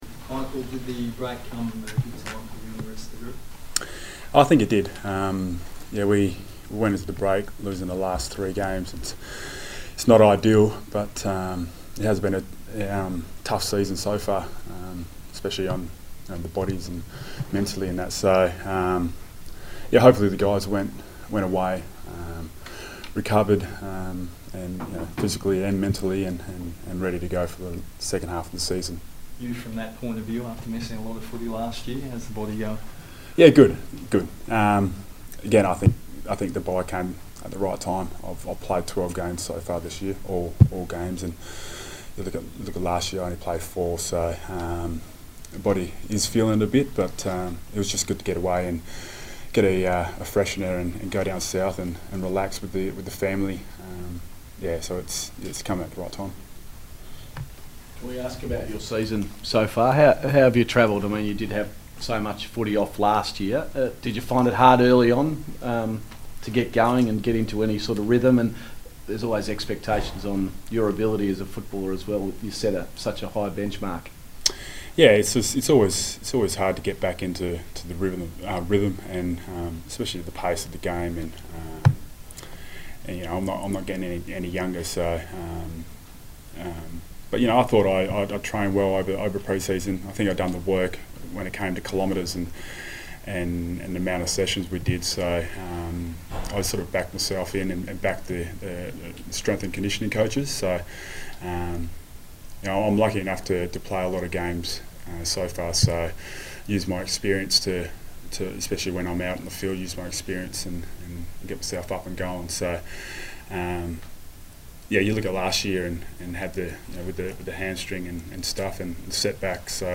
Michael Johnson media conference - Tuesday 20 June 2017
Michael Johnson spoke to the media following the bye